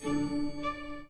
Slide open 4.wav